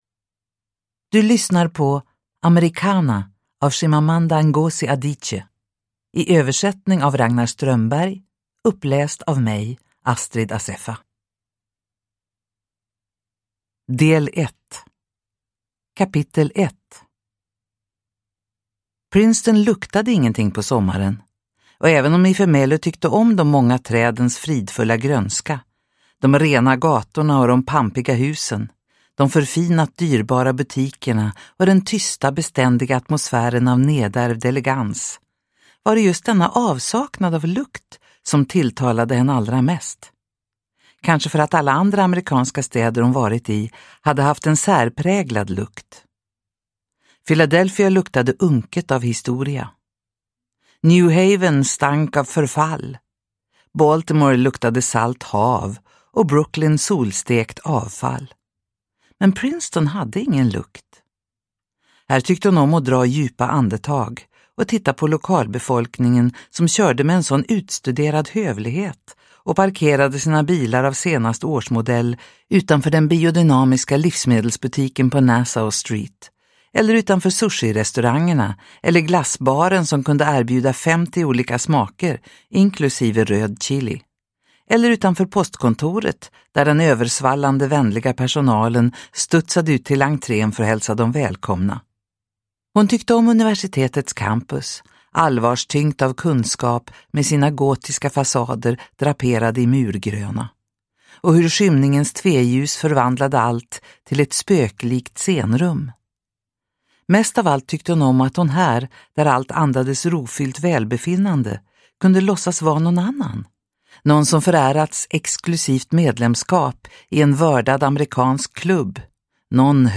Americanah – Ljudbok – Laddas ner